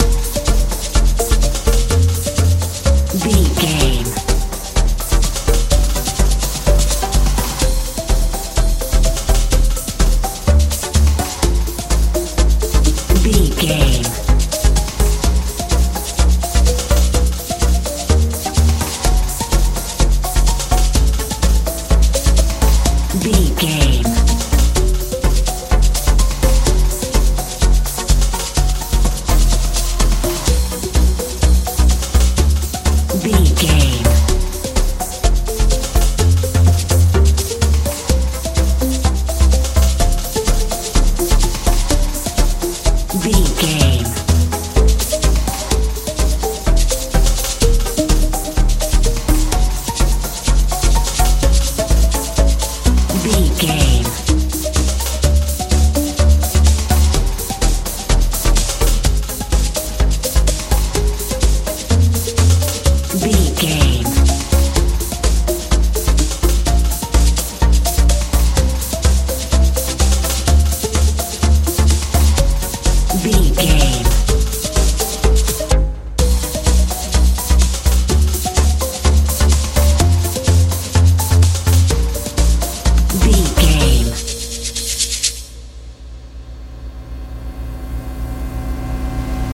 modern dance
Ionian/Major
D
dreamy
suspense
synthesiser
bass guitar
drums
80s
90s